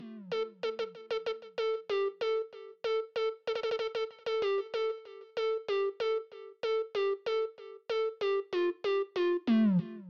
DJ Mustard型合成器
模仿DJ Mustard风格的合成器。